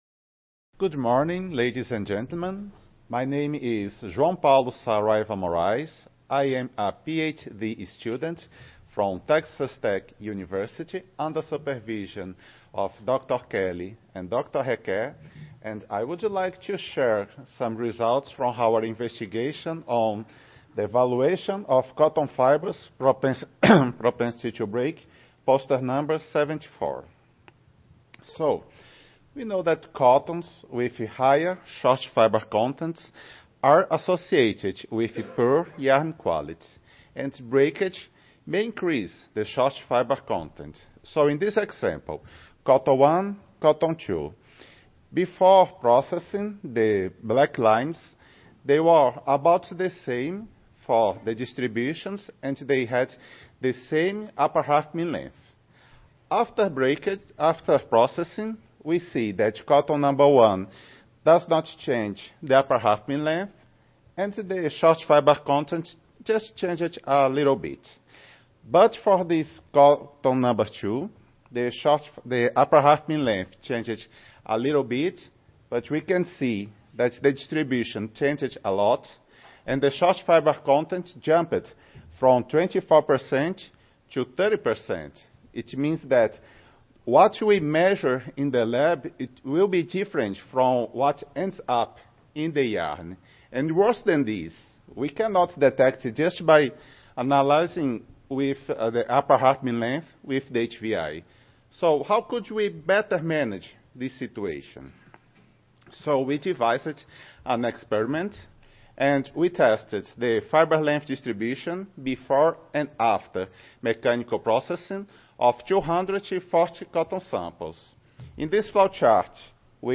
Cotton Improvement - Lightning Talks Student Competition
Audio File Recorded Presentation